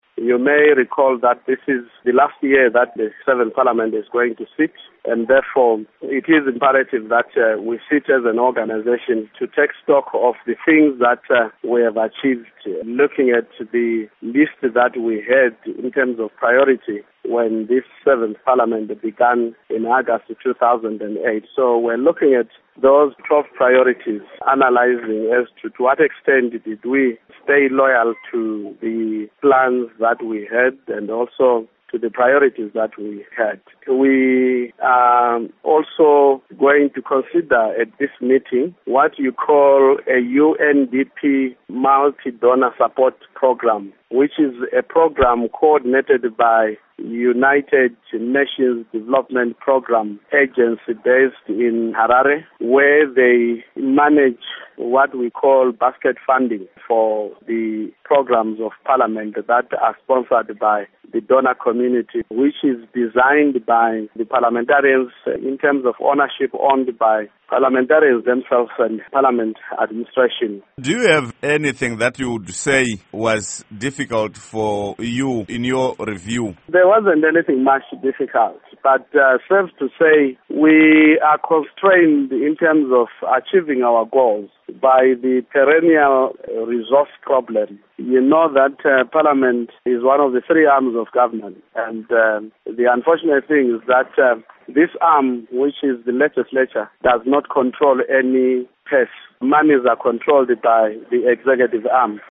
Interview With Lovemore Moyo